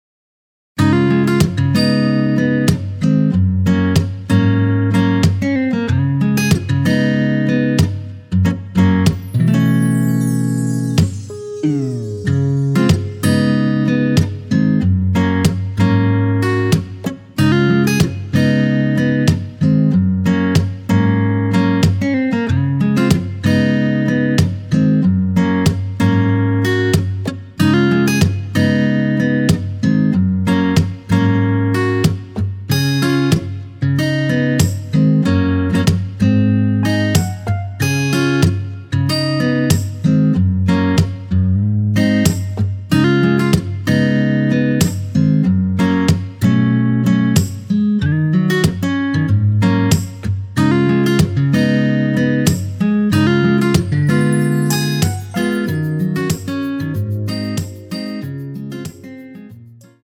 Db
앞부분30초, 뒷부분30초씩 편집해서 올려 드리고 있습니다.
중간에 음이 끈어지고 다시 나오는 이유는